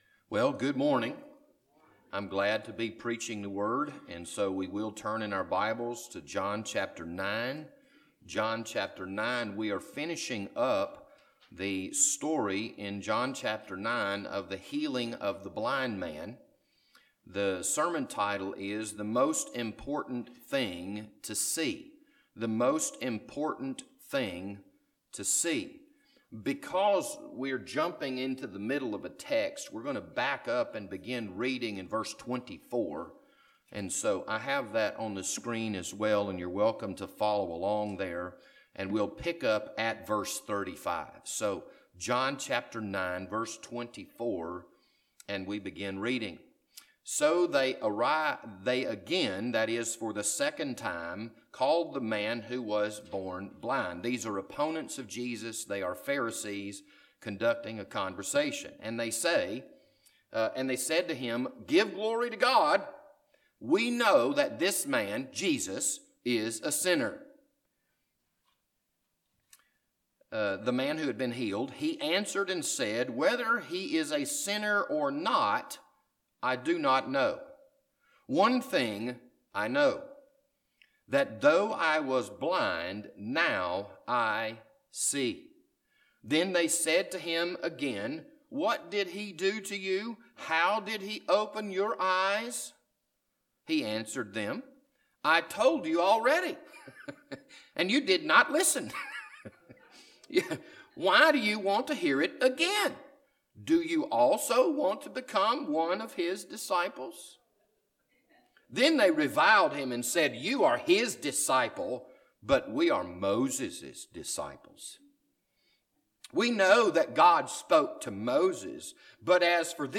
This Sunday morning sermon was recorded for June 14th, 2020.